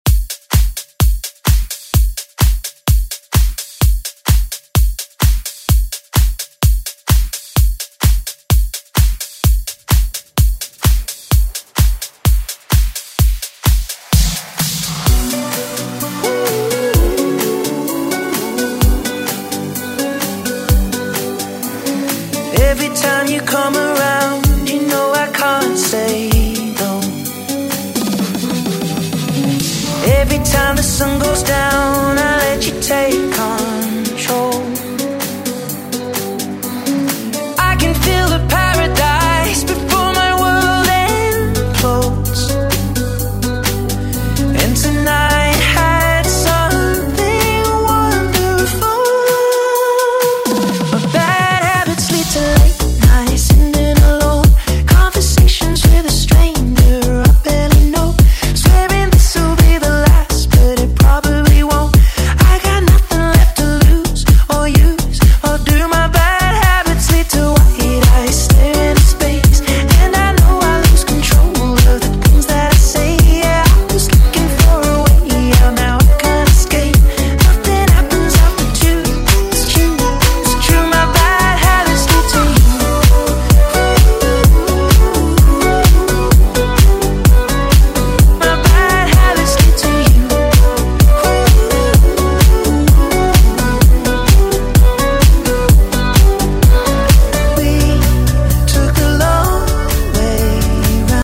Genres: 80's , DANCE , RE-DRUM